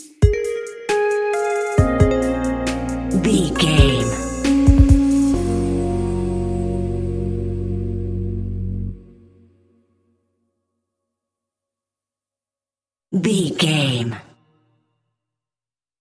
Chilled Rap Electronica Music Stinger.
Aeolian/Minor
B♭
Slow
laid back
groove
hip hop drums
hip hop synths
piano
hip hop pads